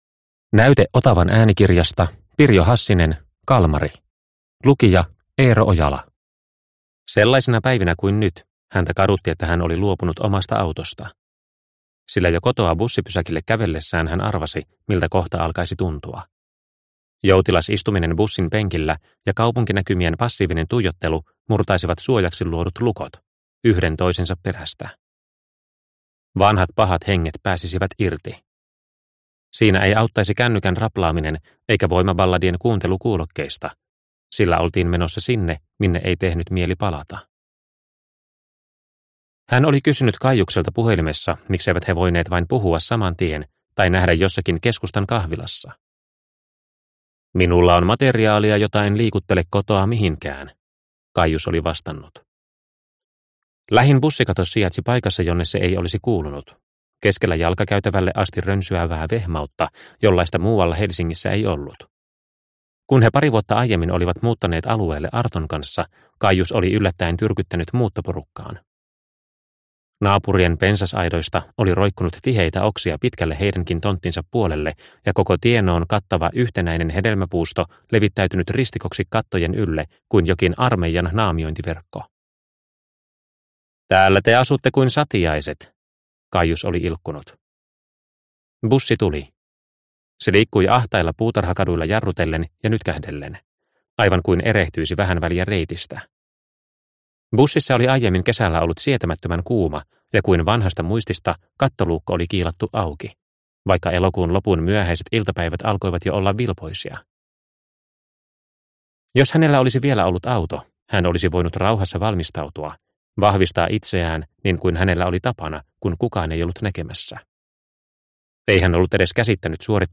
Kalmari – Ljudbok – Laddas ner